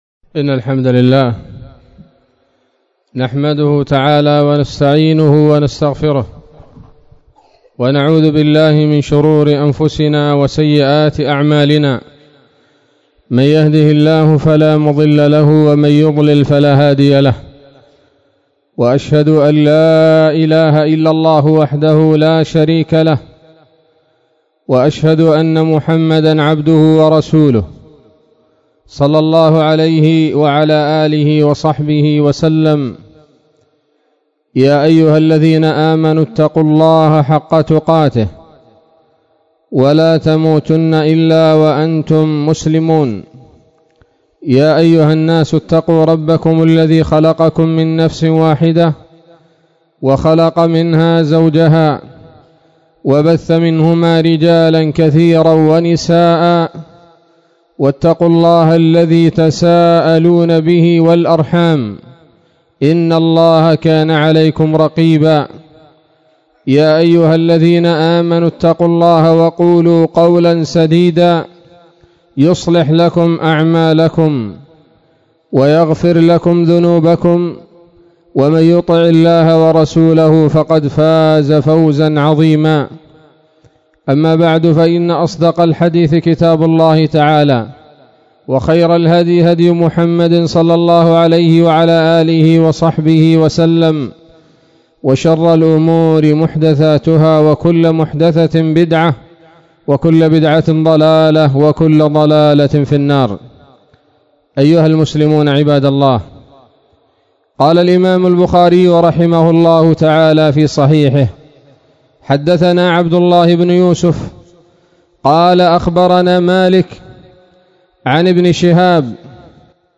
خطبة جمعة بعنوان: (( الطـــاعـــــون )) 11 من شهر رجب 1441 هـ